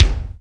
WU_BD_264.wav